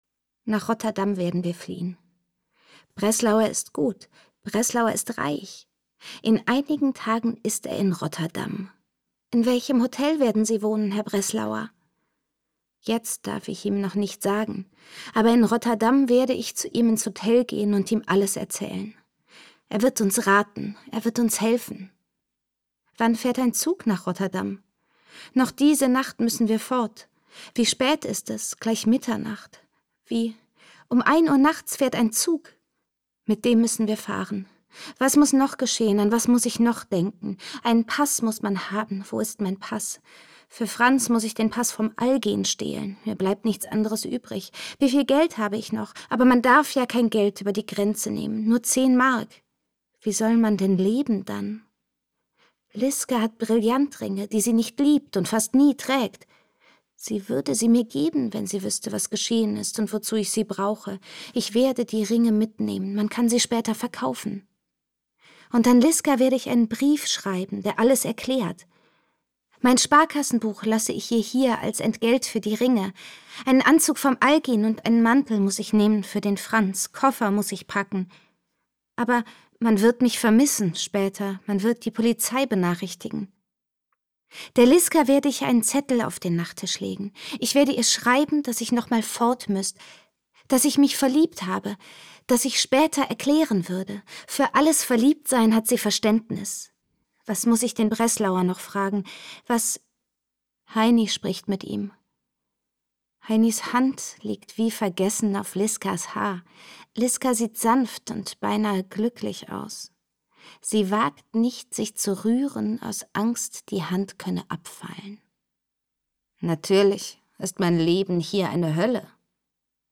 Irmgard Keun: Nach Mitternacht (15/15) ~ Lesungen Podcast